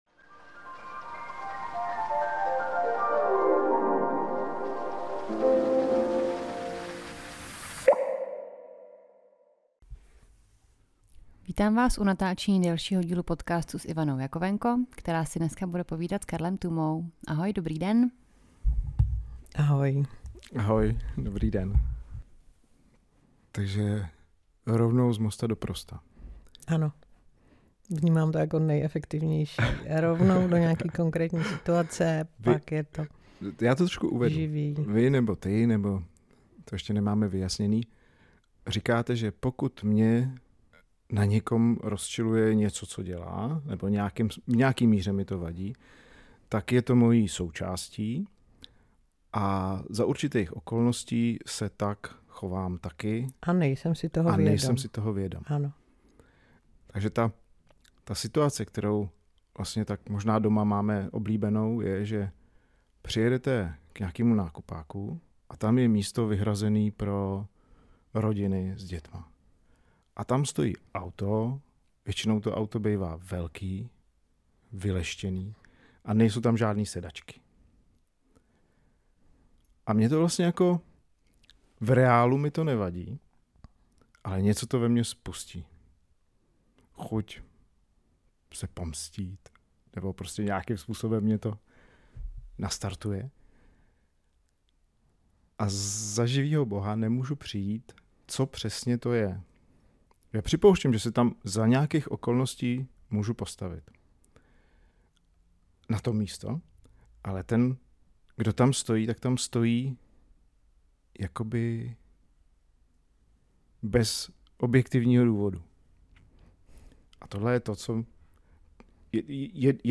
Takové povídání o životě a vnitřním a i vnějším světě.